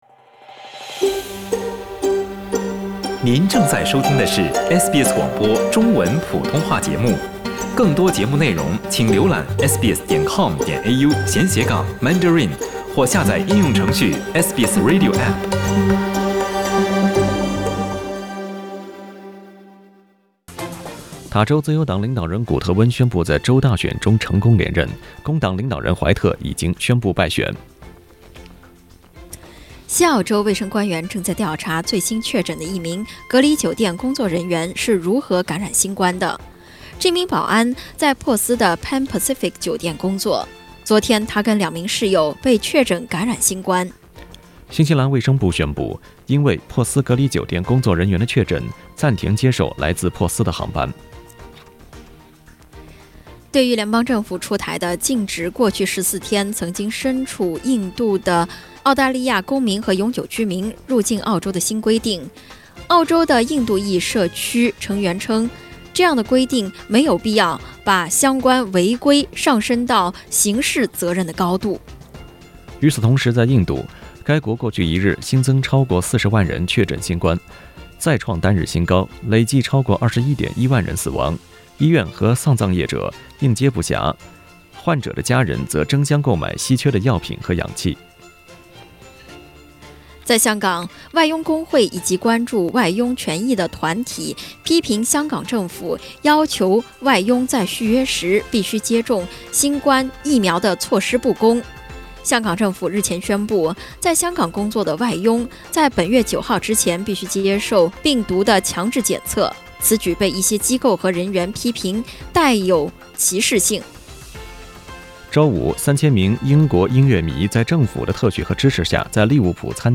SBS早新聞（5月2日）
SBS Mandarin morning news Source: Getty Images